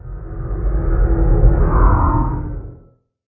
elder_idle4.ogg